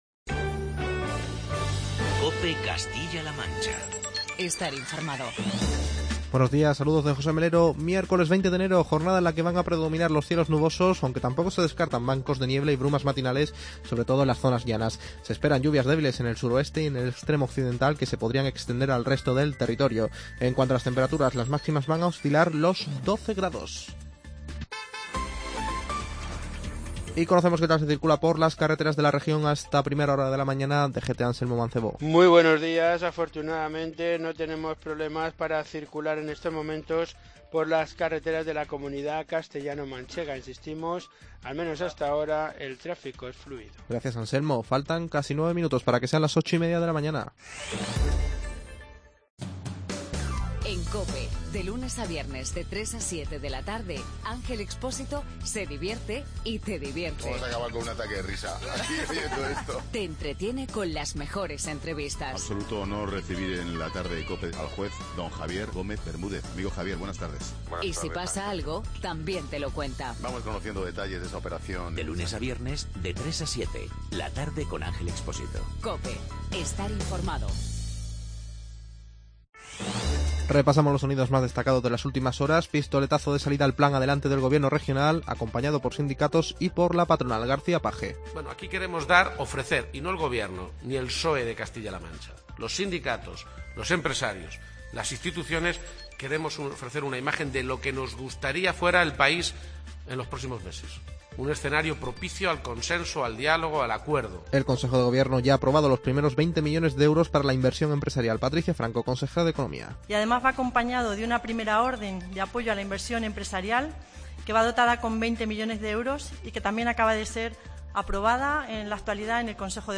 Informativo regional y provincial
Repasamos los sonidos más destacados de las últimas horas.